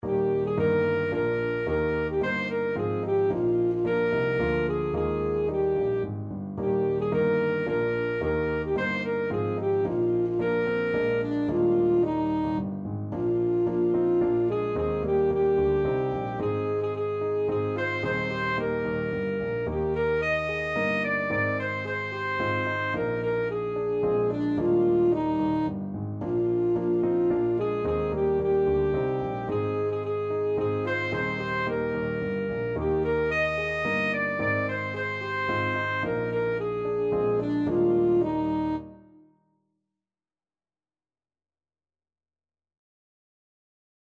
Alto Saxophone
Eb major (Sounding Pitch) C major (Alto Saxophone in Eb) (View more Eb major Music for Saxophone )
Allegro moderato = 110 (View more music marked Allegro)
3/4 (View more 3/4 Music)
Classical (View more Classical Saxophone Music)